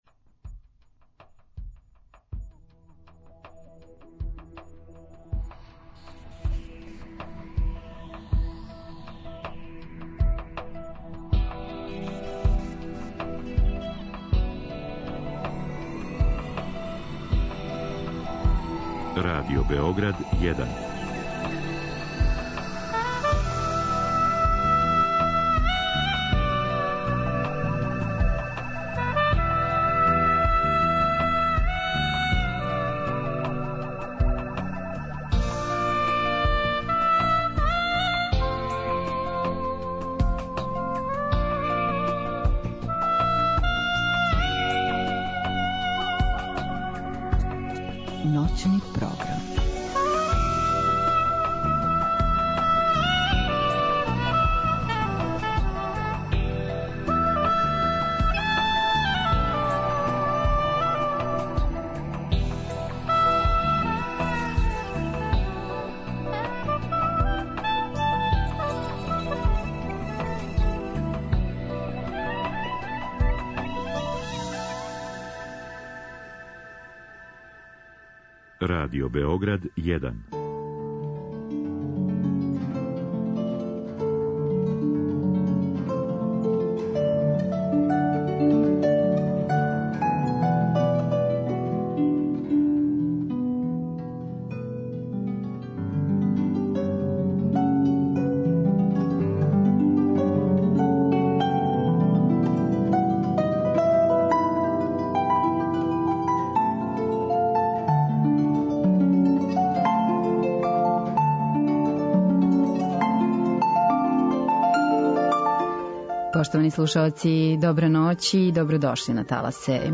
У наставку програма слушаћемо композиције Дворжака, Елгара, Русела, Брамса, Равела и Менделсона.